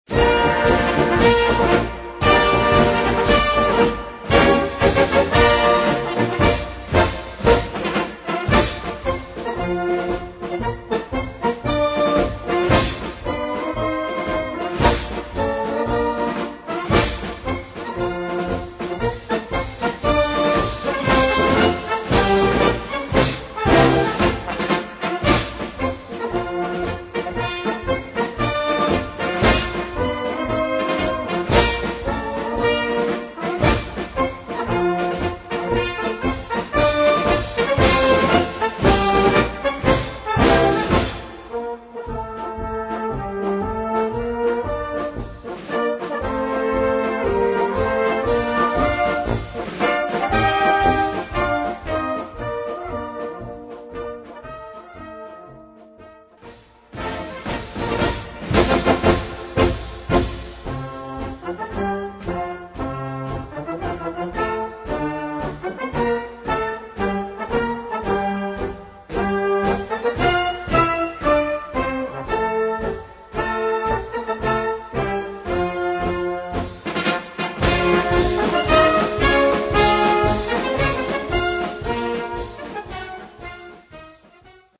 Gattung: Marsch im 2/4el Takt
Besetzung: Blasorchester
Regiments- und Defiliermarsch des ehem. k.u.k. Österr.